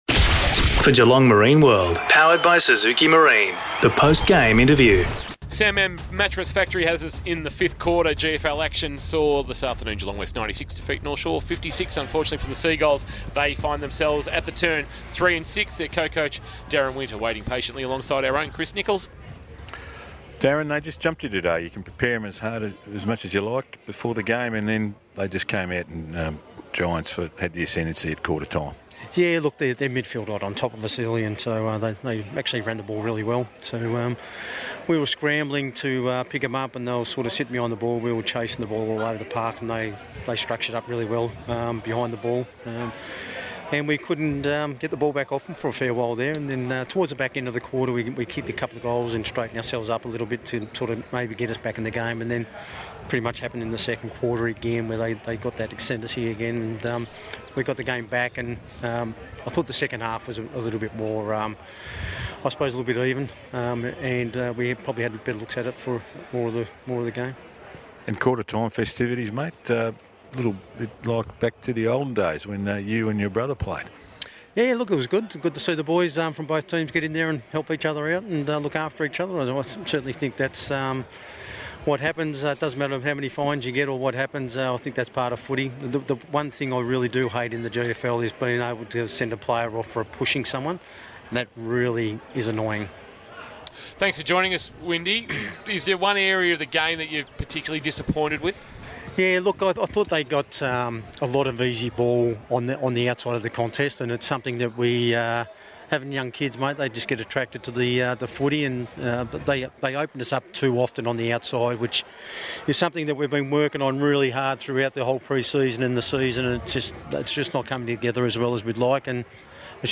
2022 – GFL ROUND 9 – GEELONG WEST vs. NORTH SHORE: Post-match Interview